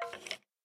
Minecraft Version Minecraft Version snapshot Latest Release | Latest Snapshot snapshot / assets / minecraft / sounds / mob / skeleton / say2.ogg Compare With Compare With Latest Release | Latest Snapshot